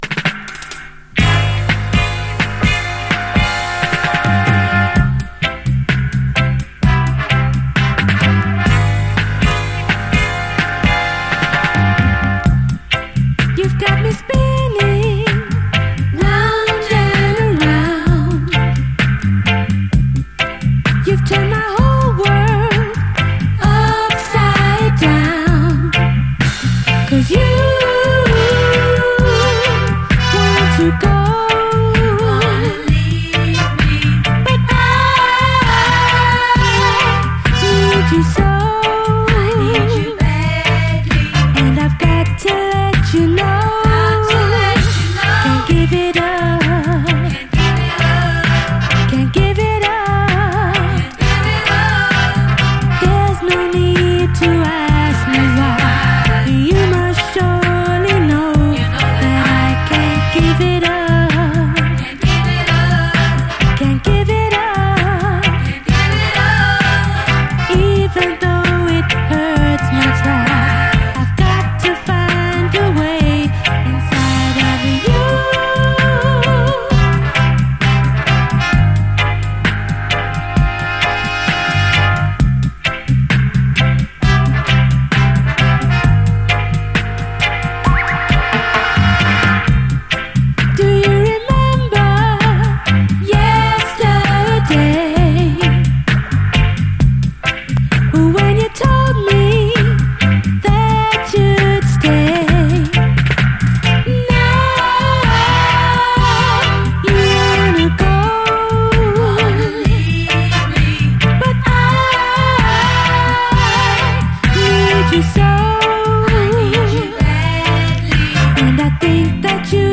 REGGAE
妖艶でビターな哀愁ラヴァーズ
甘く胸キュンなメロディー＆コーラス、そして重厚でファットなバックの演奏が見事な
ギラギラと悩ましく響くギターがカッコよく、妖艶でビターで大人っぽい哀愁ラヴァーズ